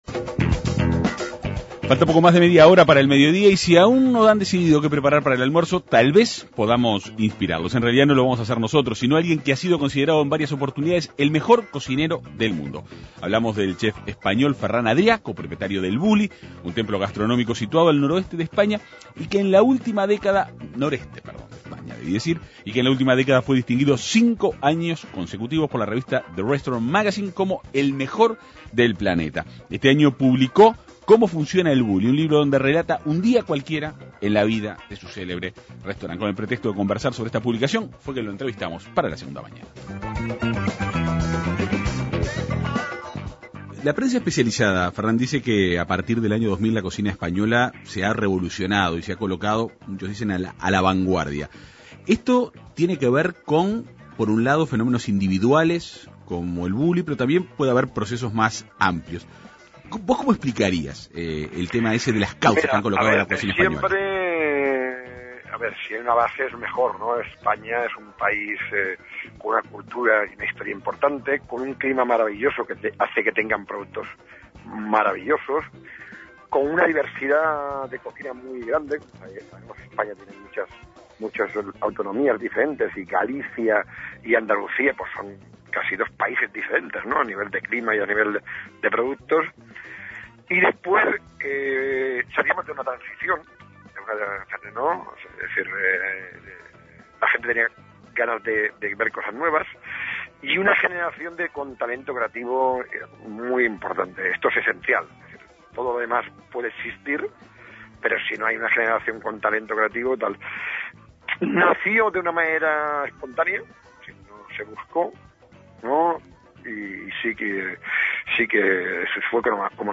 Ha sido considerado, en varias oportunidades, el mejor cocinero del mundo. El español Ferrán Adriá, co-propietario de El Bulli, un templo gastronómico situado al noreste de España, dialogó en la Segunda Mañana de En Perspectiva.